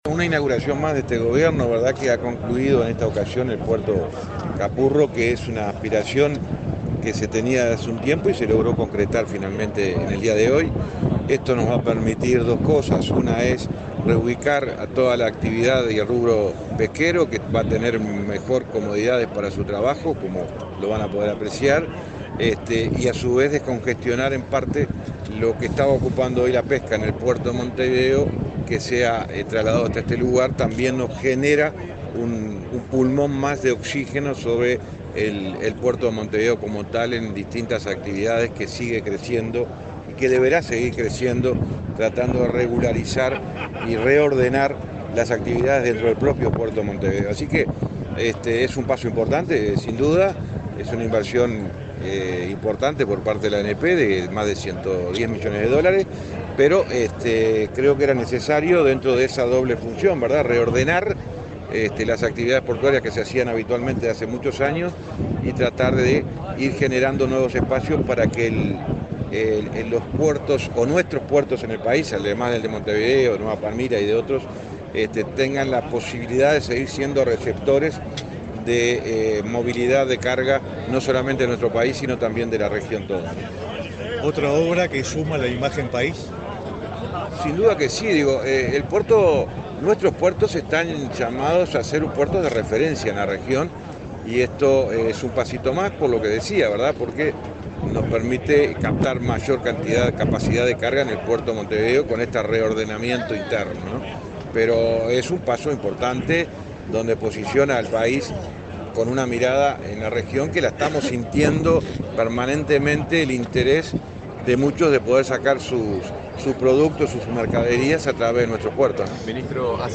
Declaraciones del ministro de Transporte, José Luis Falero
El ministro de Transporte, José Luis Falero, dialogó con la prensa, antes de participar de la inauguración del Puerto Capurro, en Montevideo.